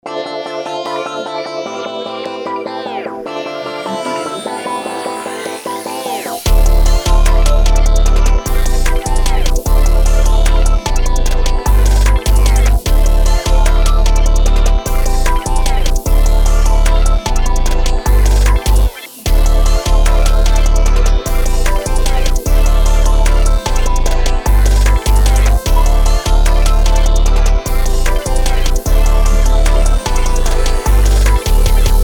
BPM: 150 BPM
Key: A minor